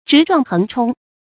直撞橫沖 注音： ㄓㄧˊ ㄓㄨㄤˋ ㄏㄥˊ ㄔㄨㄙ 讀音讀法： 意思解釋： 形容向前突破，不可阻擋或毫無顧忌地亂沖亂闖。